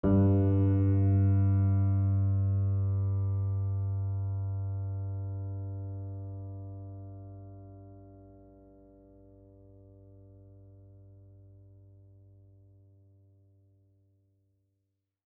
GreatAndSoftPiano